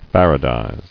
[far·a·dize]